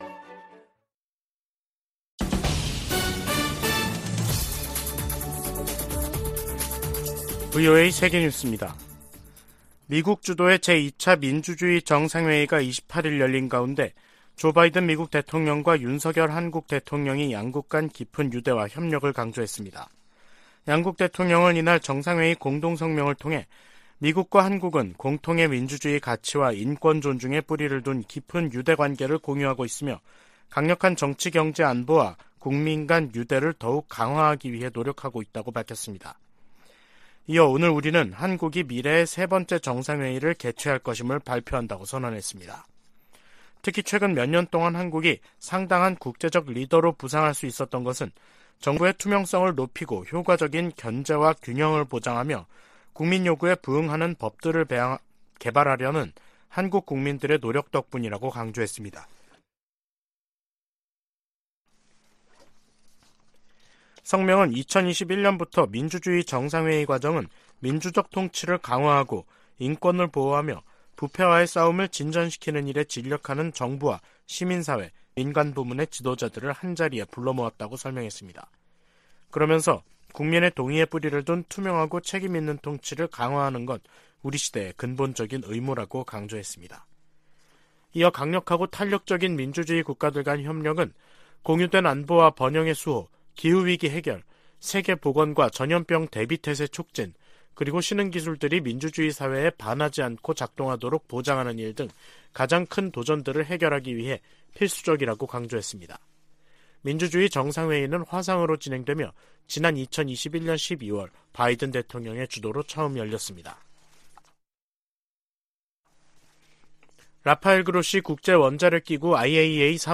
VOA 한국어 간판 뉴스 프로그램 '뉴스 투데이', 2023년 3월 29일 3부 방송입니다. 백악관은 북한의 전술핵탄두 공개에 국가 안보와 동맹의 보호를 위한 준비태세의 중요성을 강조했습니다. 국무부는 북한의 '핵 공중폭발 시험' 주장에 불안정을 야기하는 도발행위라고 비난했습니다. 전문가들은 북한이 핵탄두 소형화에 진전을 이룬 것으로 평가하면서 위력 확인을 위한 추가 실험 가능성이 있다고 내다봤습니다.